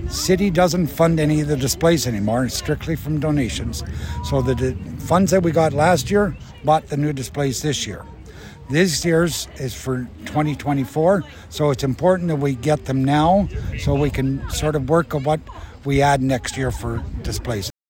Councillor and Festival of Lights Committee Chair Garnet Thompson says they are always working a year ahead.